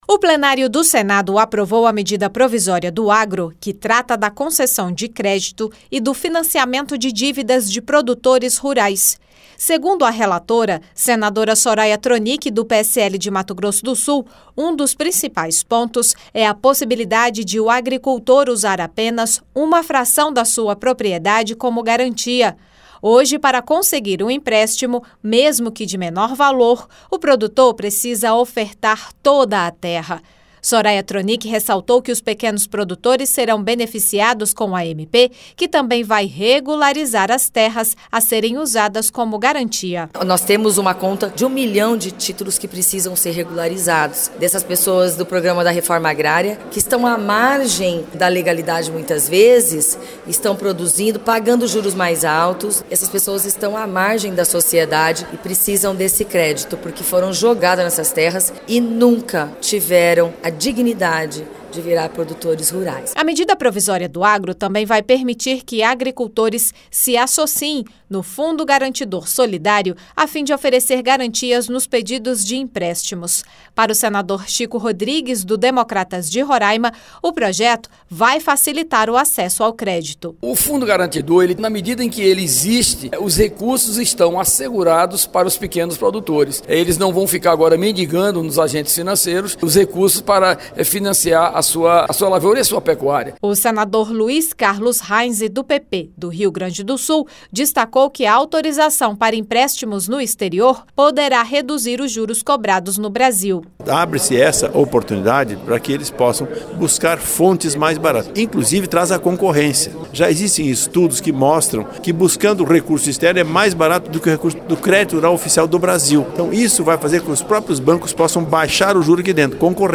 O Plenário do Senado aprovou a MP do Agro que trata da concessão de crédito e do financiamento de dívidas de produtores rurais. A relatora, senadora Soraya Thronicke (PSL-MS), destacou que a regularização de terras facilitará a obtenção de empréstimos pelos agricultores. O senador Chico Rodrigues (DEM-RR) destacou o Fundo Garantidor Solidário que permitirá a associação de produtores para darem garantias nos empréstimos.